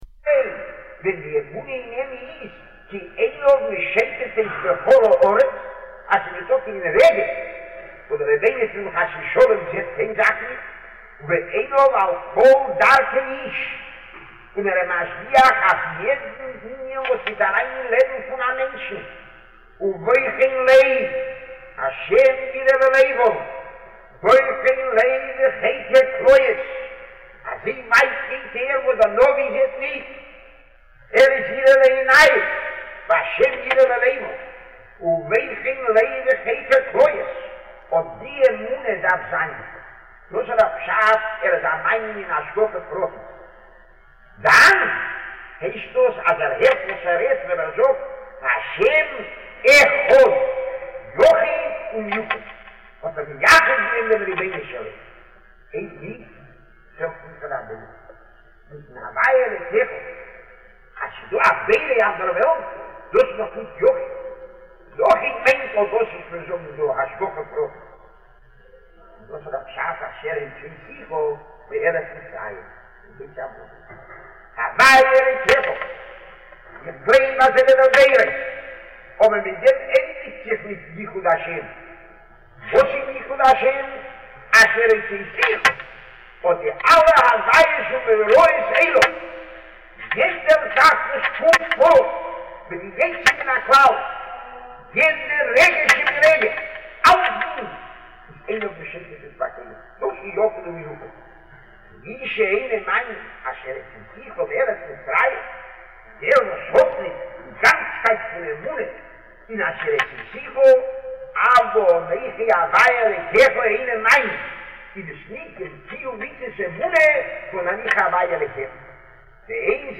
Rav Gifter giving shiur on Orchos Chaim chapter 22.